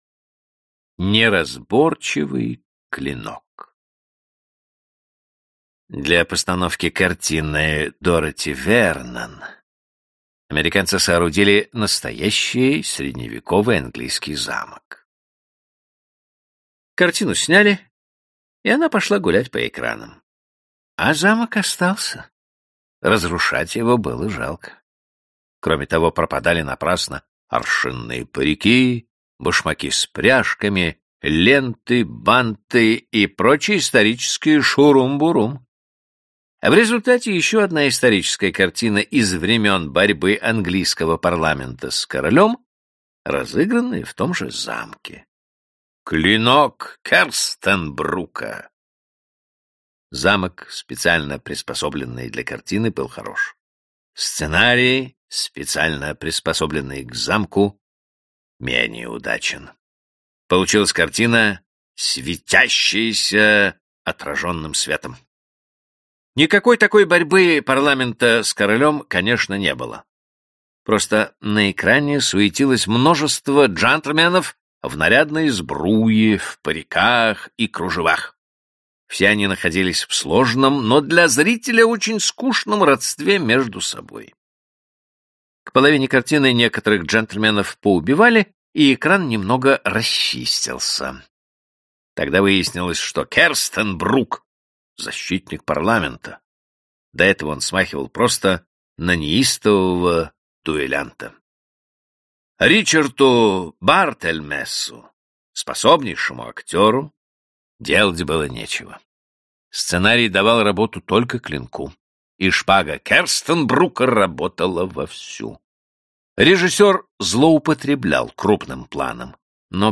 Аудиокнига Источник веселья. Сборник рассказов | Библиотека аудиокниг